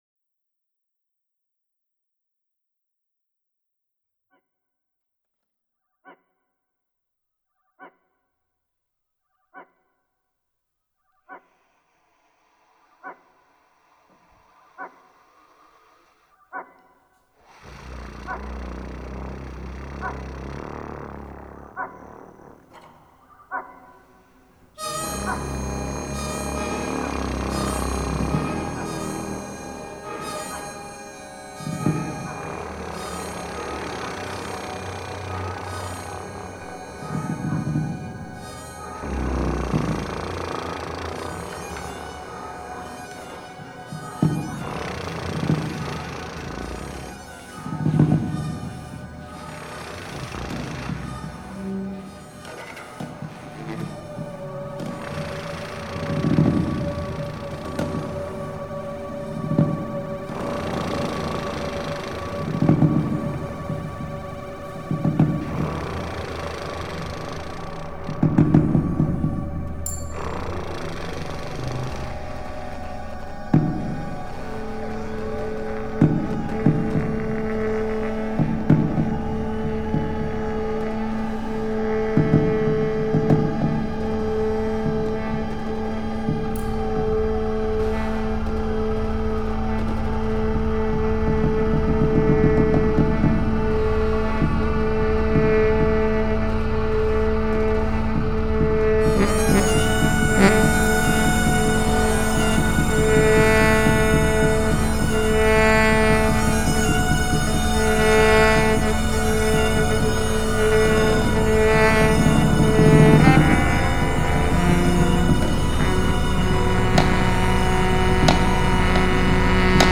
01_Trio.mp3